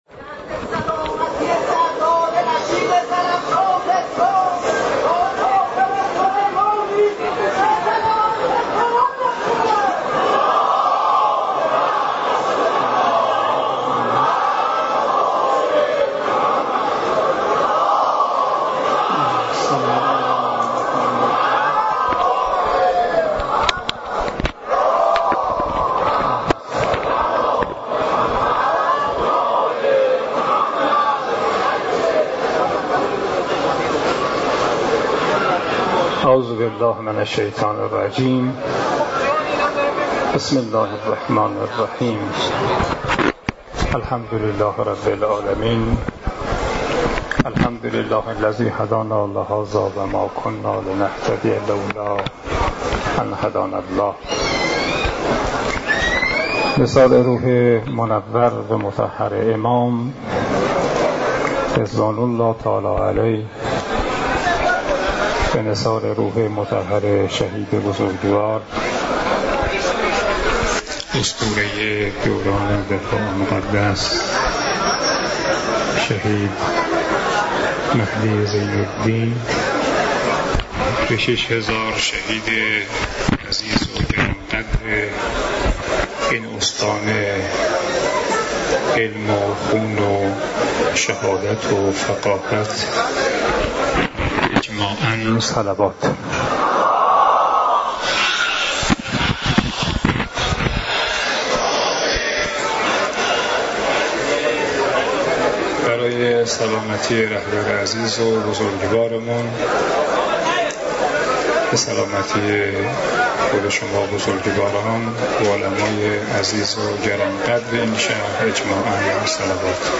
سخنرانی شهید سردار قاسم سلیمانی در یادواره شهید مهدی زین الدین